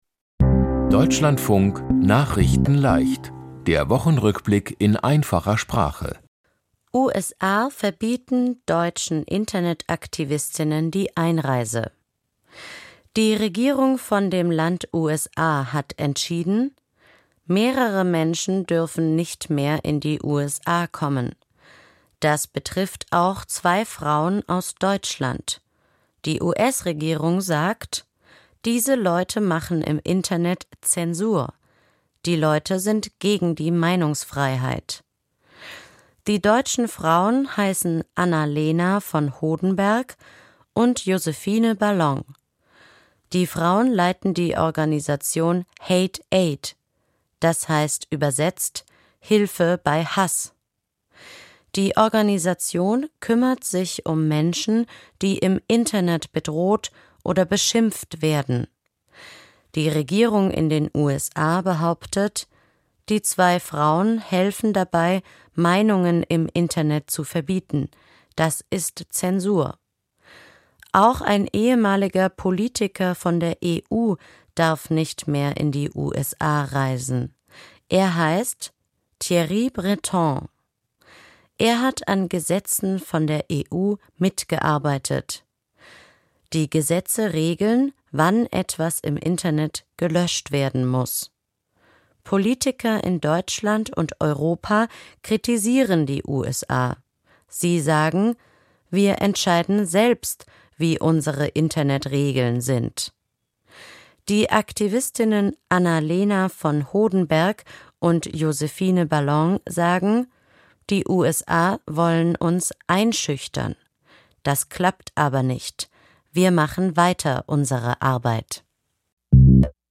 Die Themen diese Woche: USA verbieten deutschen Internet-Aktivistinnen die Einreise, Erste Abschiebung von Deutschland nach Syrien seit 2011, Papst Leo ruft zu Frieden auf, Mehr Feuerwerk in den Geschäften, Vor 70 Jahren suchte Deutschland Gast-Arbeiter aus dem Land Italien und Zum 1. Mal war eine Roll-Stuhl-Fahrerin im Welt-Raum. nachrichtenleicht - der Wochenrückblick in einfacher Sprache.